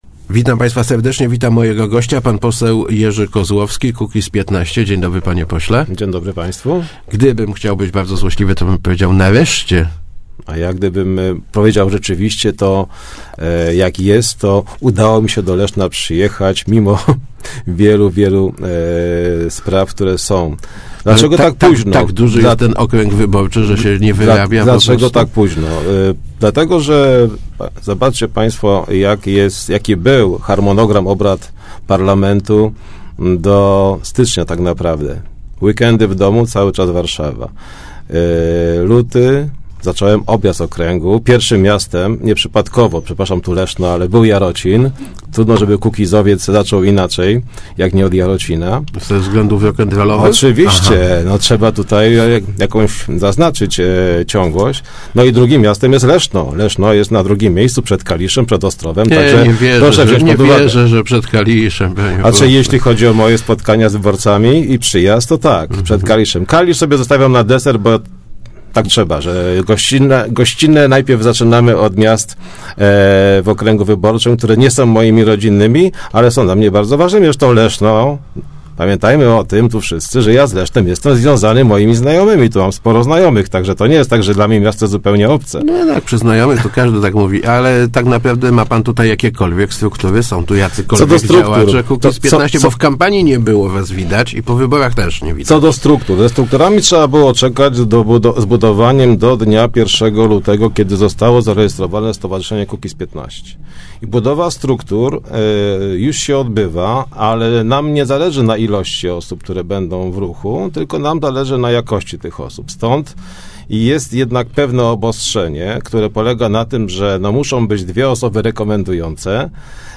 - Stanowimy now� jako�� w Sejmie, poniewa� nie jeste�my zainteresowani �adnymi stanowiskami - mówi� w Rozmowach Elki pose� Jerzy Koz�owski.